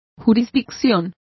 Complete with pronunciation of the translation of jurisdiction.